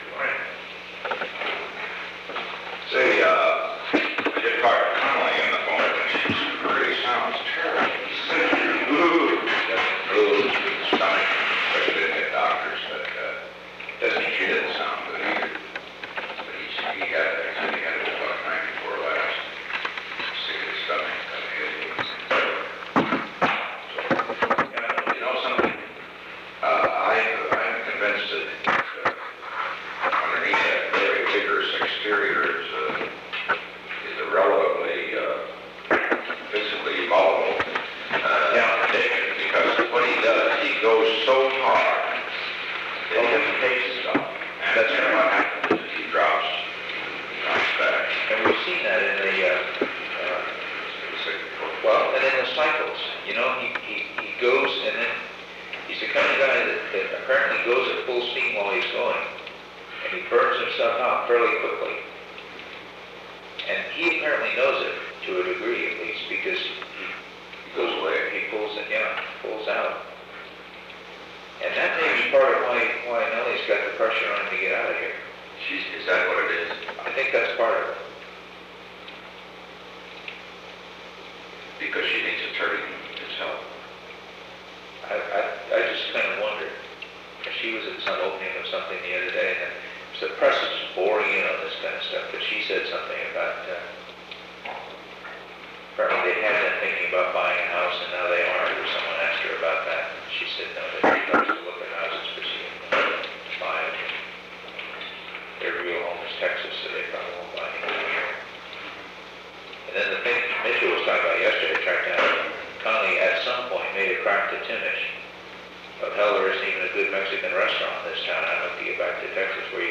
The Oval Office taping system captured this recording, which is known as Conversation 660-006 of the White House Tapes.